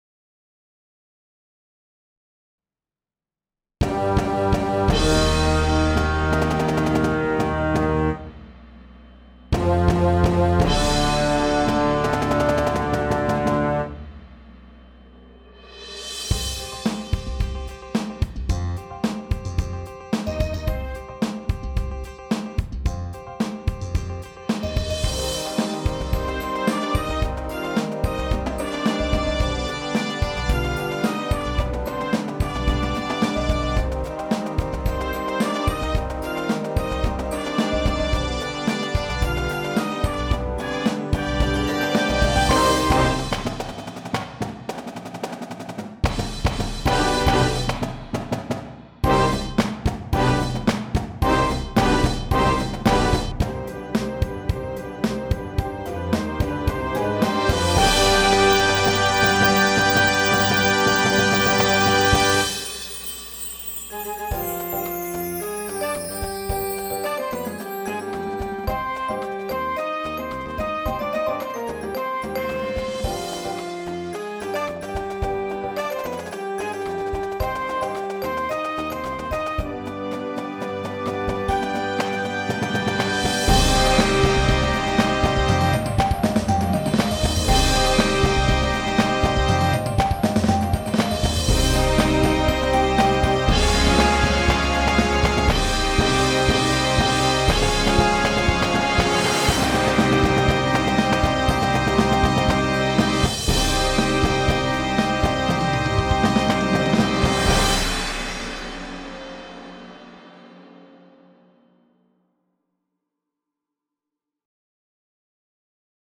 Full of groove, aggressive energy and competitive edge.
• Flute
• Alto Saxophone
• Trumpet 1, 2
• Tuba
• Snare Drum
• Bass Drums
• Bass Guitar